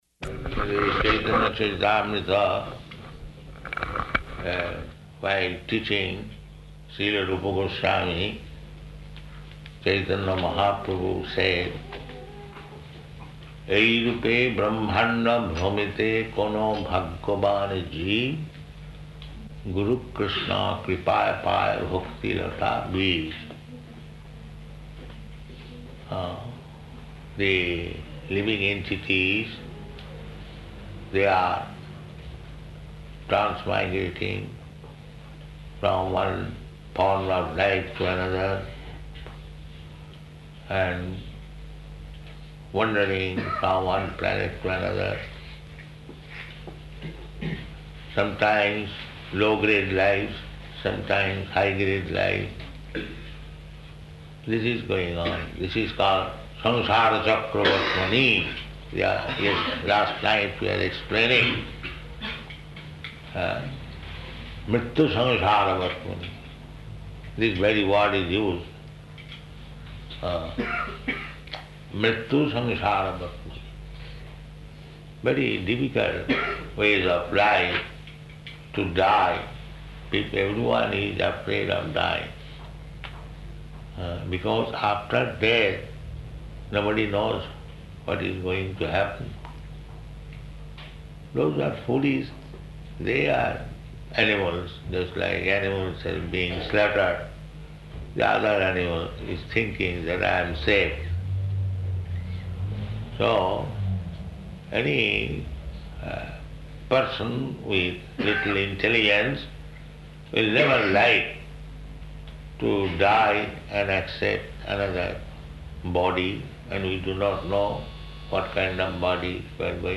Initiation Lecture [partially recorded]
Location: Melbourne